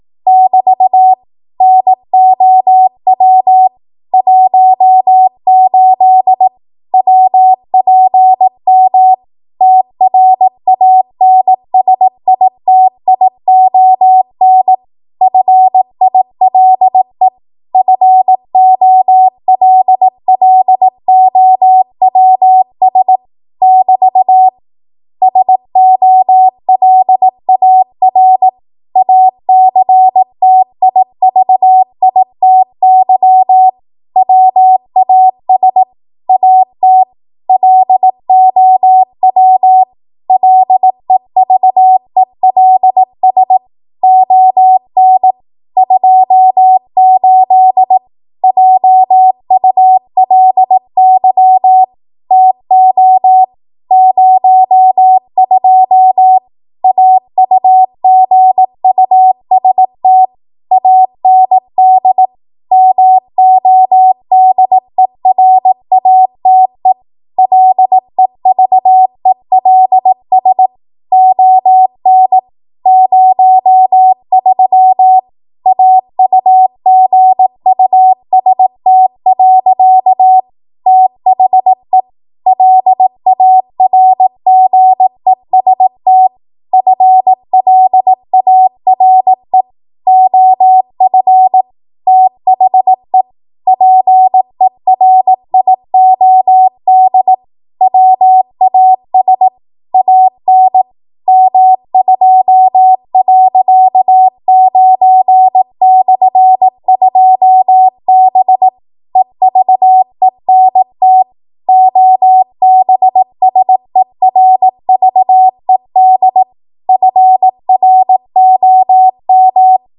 18 WPM Code Archive